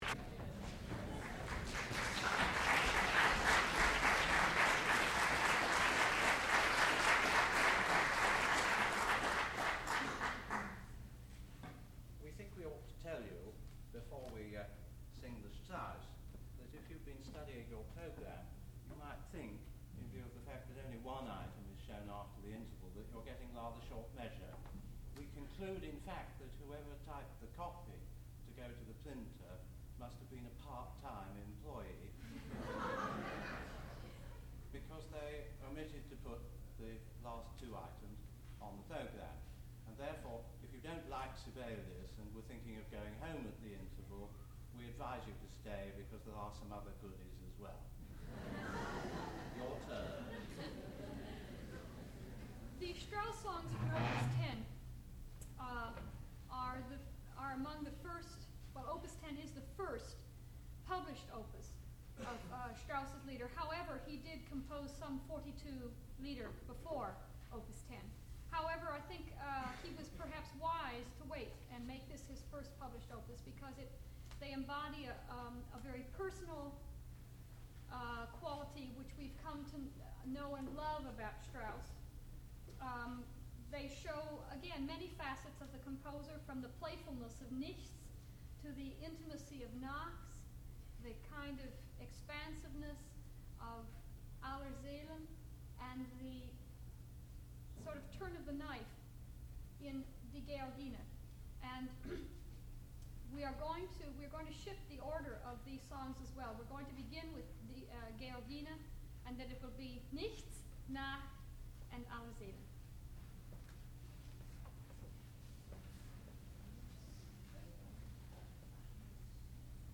sound recording-musical
classical music
soprano
piano and harpsichord